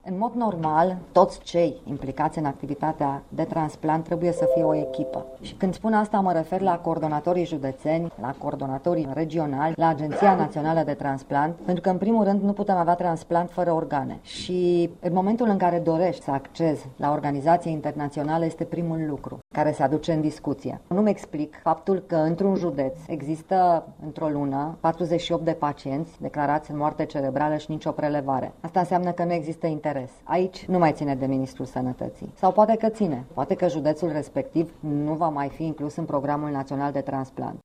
Legea transplantului a fost finalizată şi ar putea intra în dezbatere publică luna viitoare, a anunţat ministrul Sănătăţii, Sorina Pintea. Ea a spus, la un eveniment de profil, că Programul Naţional de Transplant este utilizat ineficient, dar că noile prevederi legale vor revoluţiona transplantul în ţara noastră: